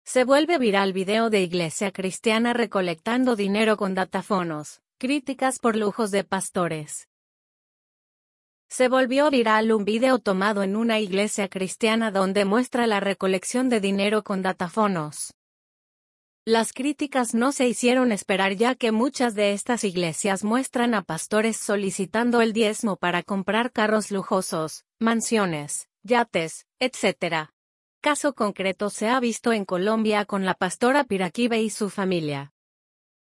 se volvió viral un vídeo tomado en una iglesia cristiana donde muestra la recolección de dinero con datafonos.